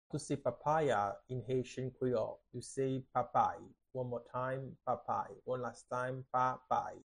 How to say "Papaya" in Haitian Creole - "Papay" pronunciation by a native Haitian Teacher
“Papay” Pronunciation in Haitian Creole by a native Haitian can be heard in the audio here or in the video below:
How-to-say-Papaya-in-Haitian-Creole-Papay-pronunciation-by-a-native-Haitian-Teacher.mp3